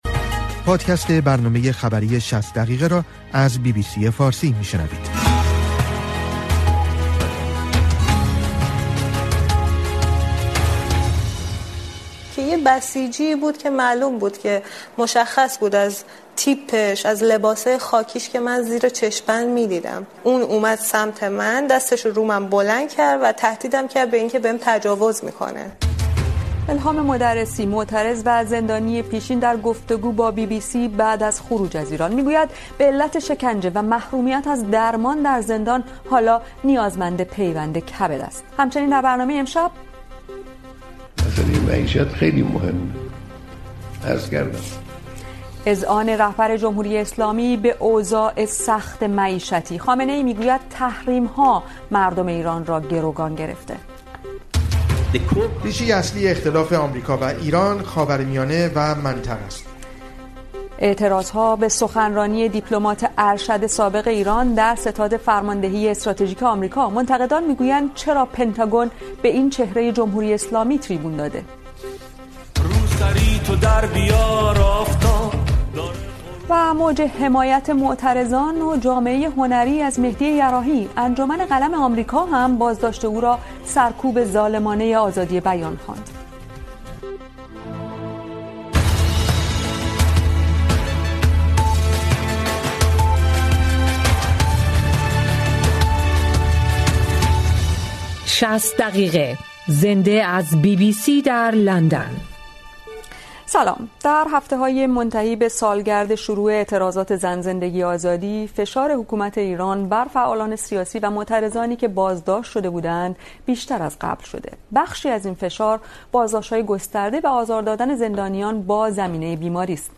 برنامه خبری ۶۰ دقیقه چهارشنبه ۸ شهریور ۱۴۰۲